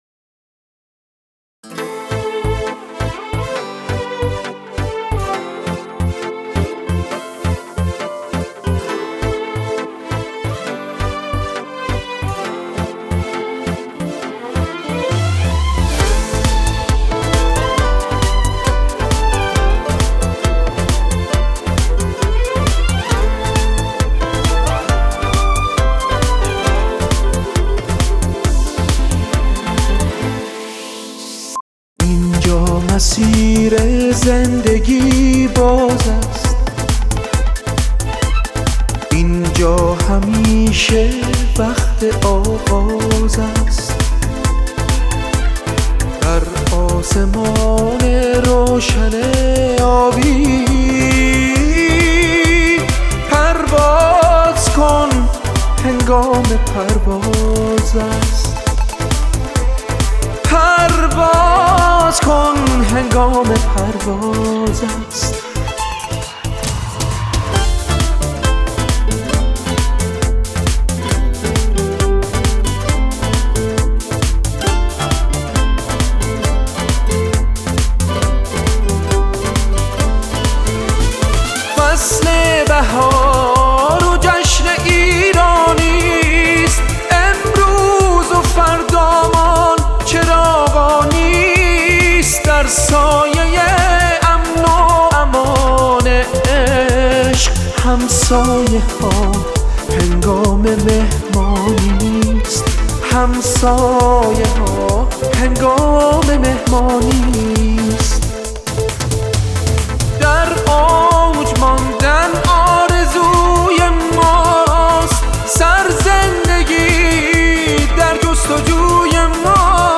تیتراژ